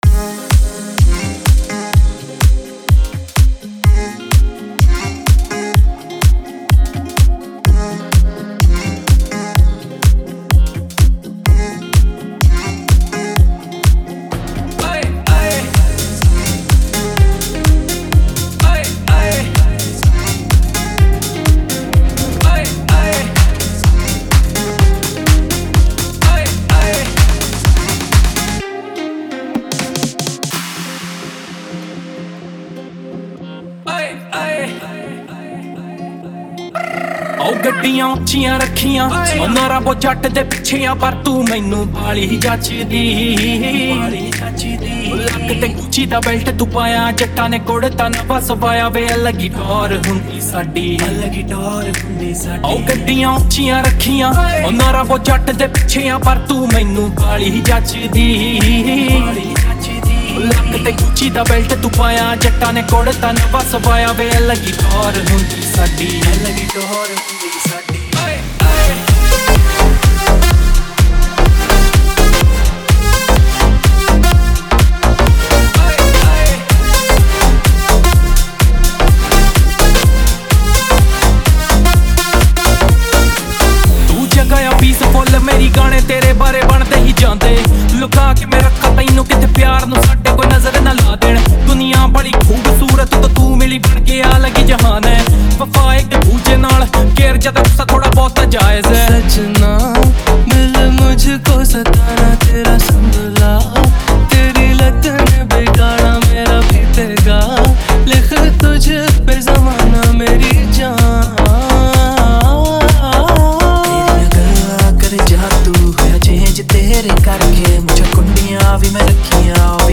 Punjabi DJ Remix Songs